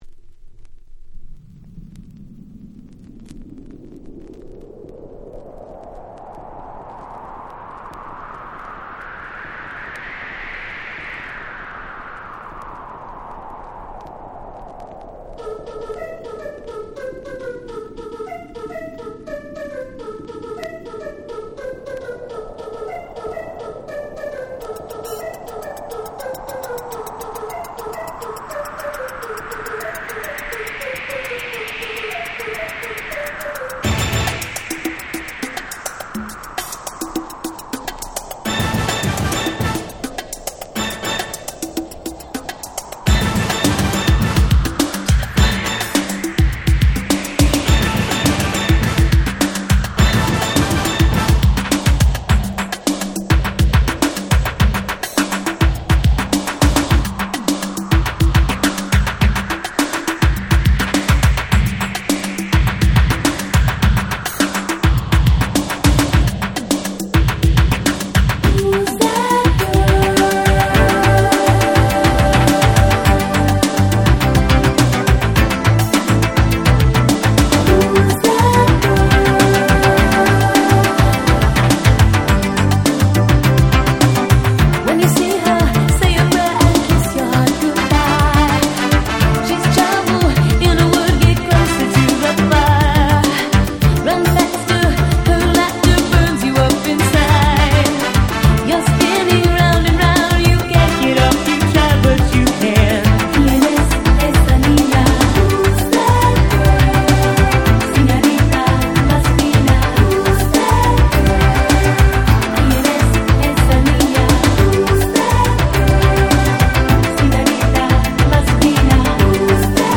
87' Super Hit Pops / R&B !!
彼女の魅力あふれるLatinフレイヴァーなエキゾチックサウンド！